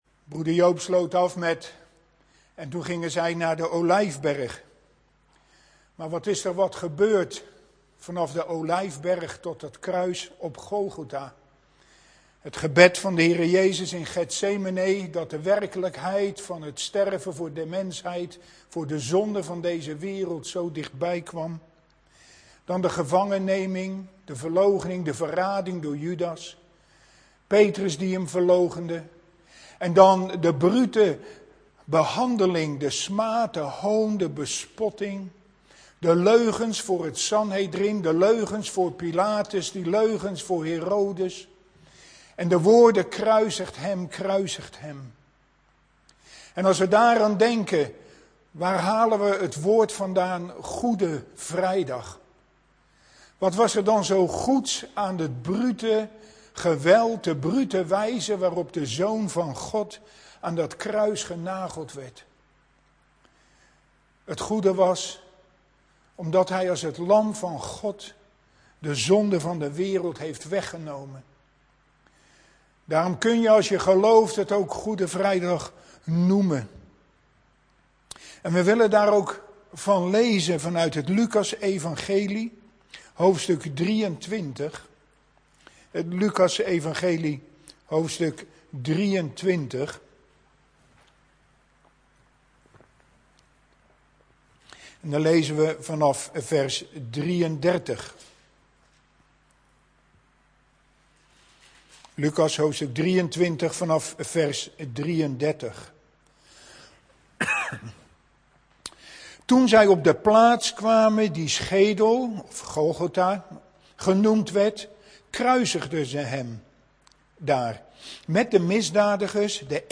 In de preek aangehaalde bijbelteksten (Statenvertaling)Lukas 23:33-5633 En toen zij kwamen op de plaats, genaamd Hoofdschedel plaats, kruisigden zij Hem aldaar, en de kwaaddoeners, den een ter rechter zijde en den ander ter linker zijde. 34 En Jezus zeide: Vader, vergeef het hun; want zij weten niet, wat zij doen.